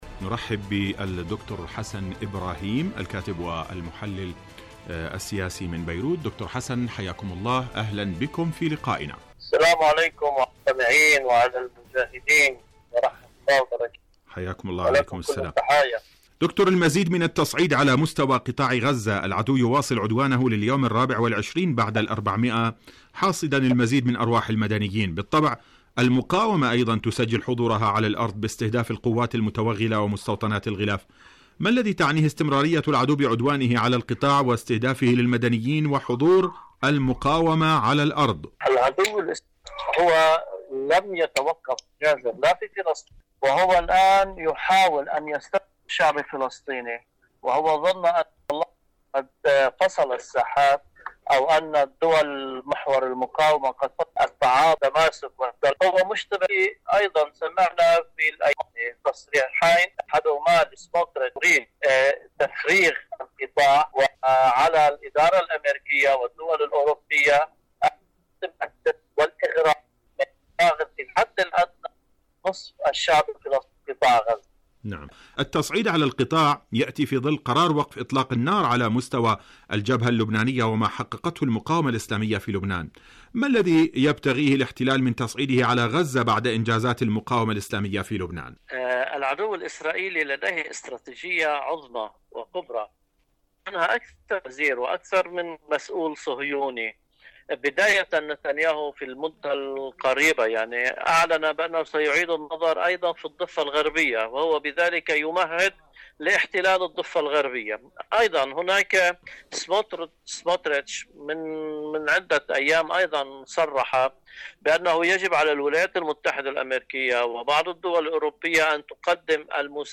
المقاومة وتثبيت معادلة الرد.. مقابلة
إذاعة طهران- فلسطين اليوم مقابلة إذاعية